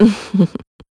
Ripine-Vox-Laugh.wav